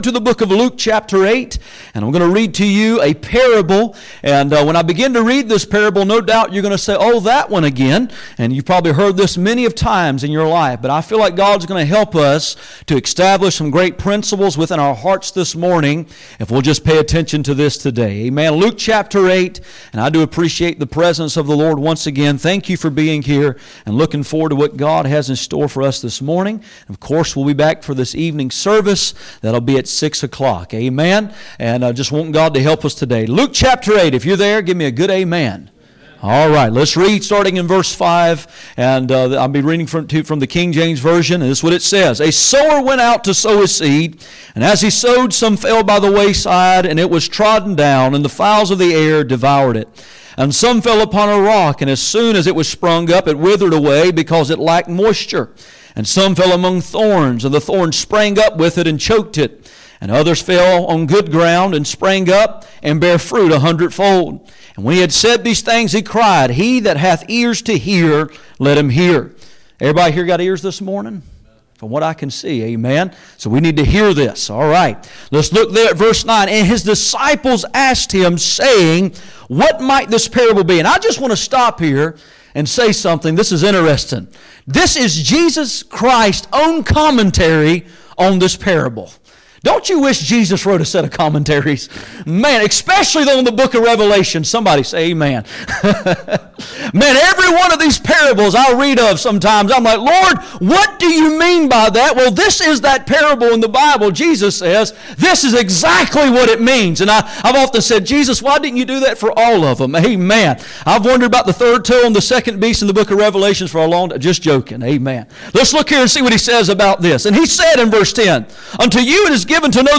Passage: Luke 8:5-15 Service Type: Sunday Morning